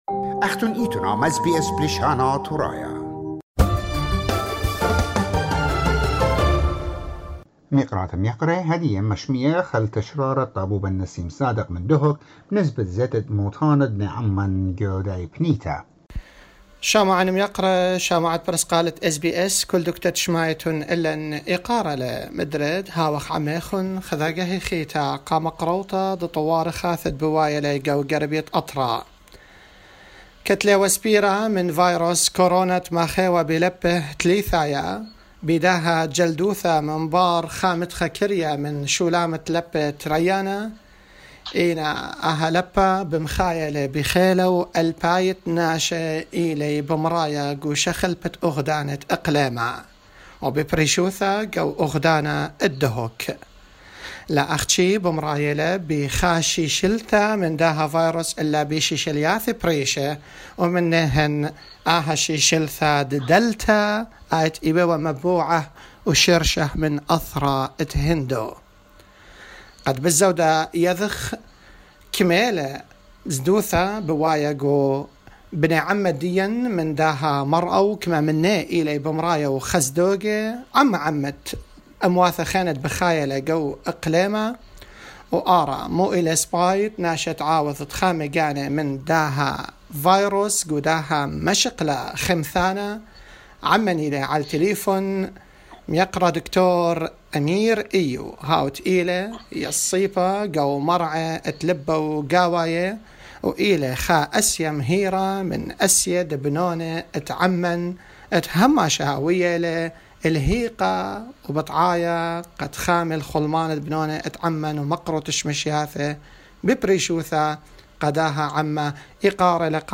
SBS Assyrian